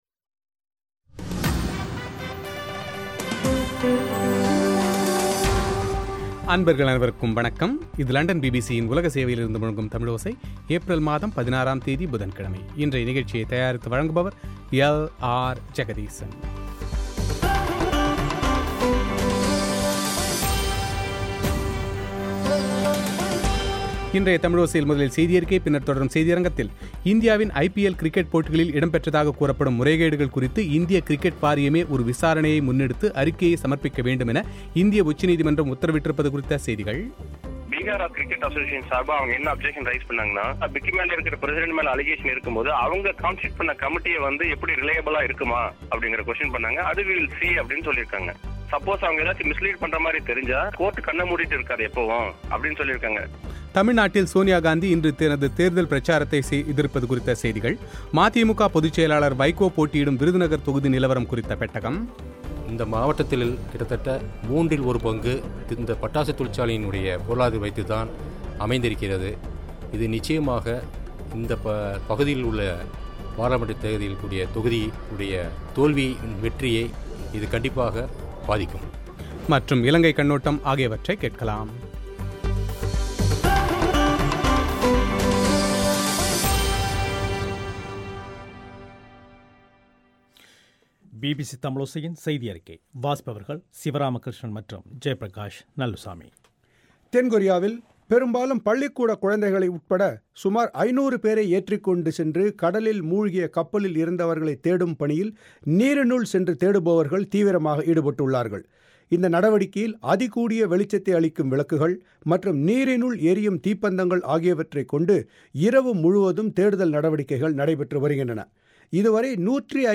“தலைமுறைகள்” திரைப்படத்திற்கு விருது கிடைத்திருப்பது பற்றி அந்த திரைப்படத்தின் தயாரிப்பாளர் இயக்குநரும் நடிகருமான சசிகுமாரின் பேட்டி;